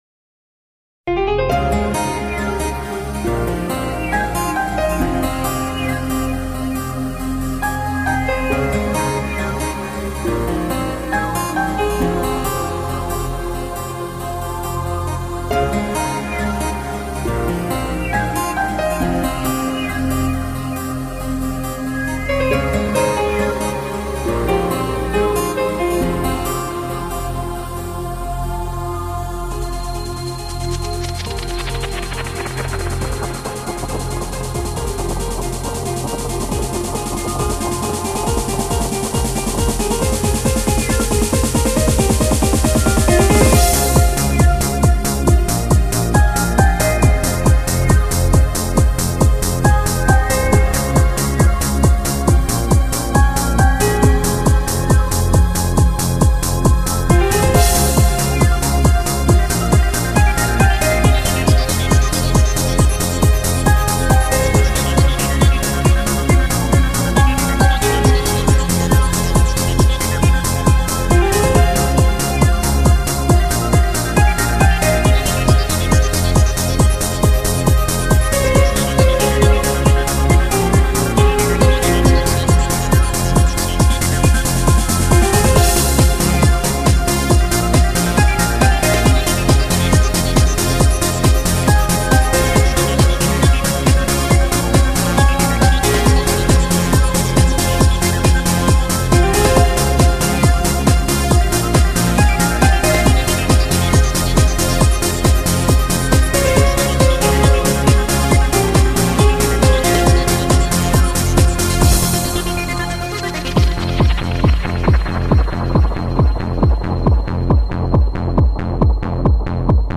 迷幻舞曲
TRANCE 狂飙电音横扫全球